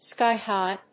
/ˈskaɪˌhaɪ(米国英語)/